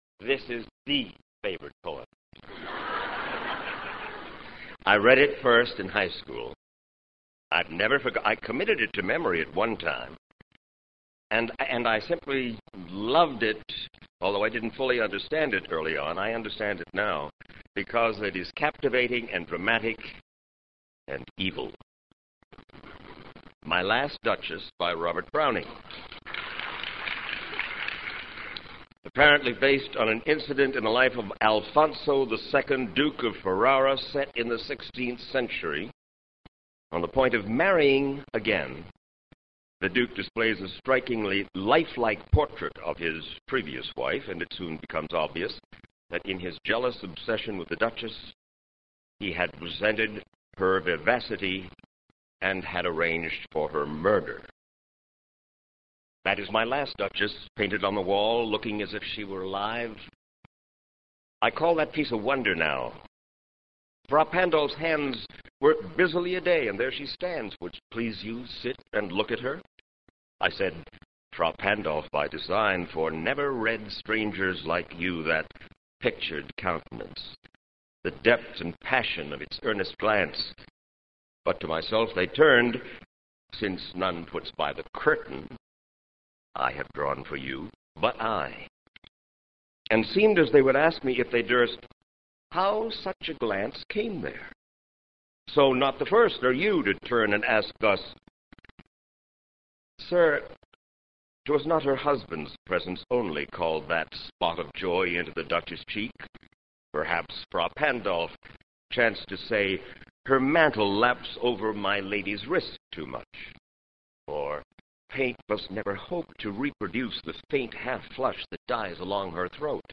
Hear Mike Wallace read Robert Browning’s “My Last Duchess.”
Wallace took part in a Favorite Poem Project reading at New York’s Town Hall in April 1998.
Wallace reads the poem rather well, and mentions having memorized it when he was in high school.
You can hear, in Mike Wallace’s voice, his expert communicator’s pleasure—the pleasure of a particular reader, in the spirit of a favorite poem—in that bit of dramatic irony.